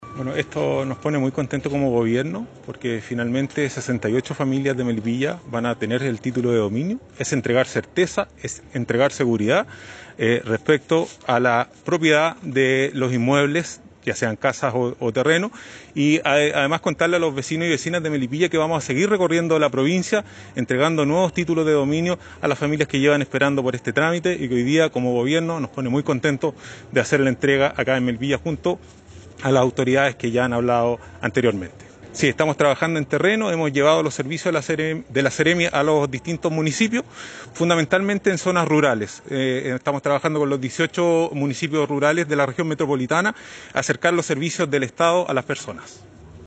Tras una significativa ceremonia, más de 60 familias de la comuna de Melipilla recibieron sus ansiados Títulos de Dominio, en una actividad presidida por la Ministra de Bienes Nacionales, Javiera Toro; la Delegada Presidencial Provincial, Sandra Saavedra; el seremi de Bienes Nacionales, Germán Pino; y la alcaldesa Lorena Olavarría.